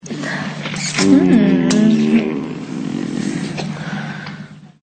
Поцелуй.mp3